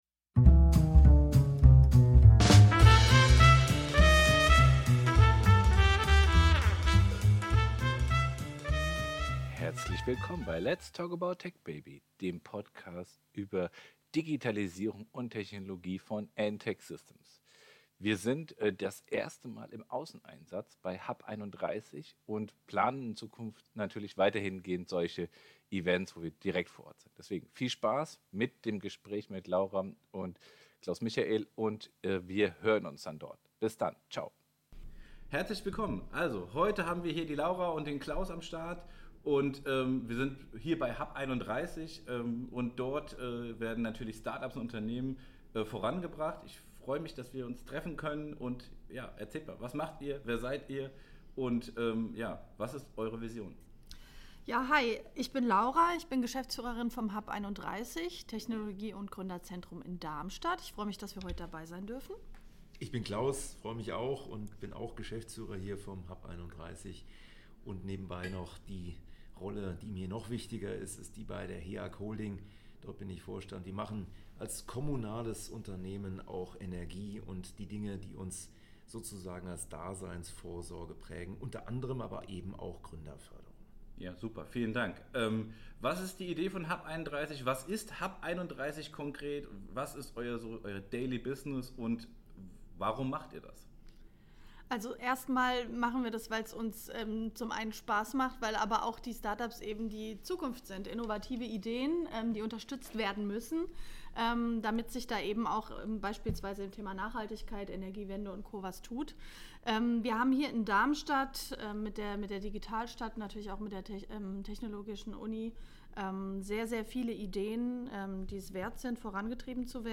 Dieses Mal haben wir etwas ganz Besonderes für euch: Unsere erste Episode mit gleich zwei Gästen!